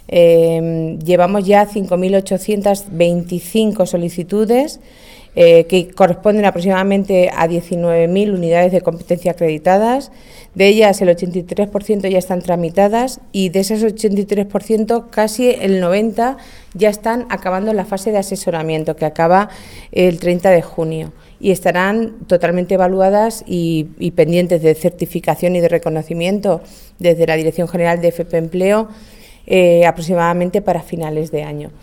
DG de Formación Profesional para el Ámbito Laboral Viernes, 3 Junio 2022 - 2:15pm La directora general de Formación por el Empleo, Marta Roldán ha explicado desde el IES “Don Bosco” que el Gobierno regional ha tramitado 5.825 solicitudes de 19.000 unidades de competencias acreditadas en toda CLM, con un 83 por ciento tramitadas y con el 90 por ciento en la fase final de asesoramiento que finaliza el 30 de junio, quedando resueltas a finales de año. marta_roldan._programa_acredita_2.mp3 Descargar: Descargar Provincia: Albacete